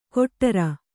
♪ koṭṭara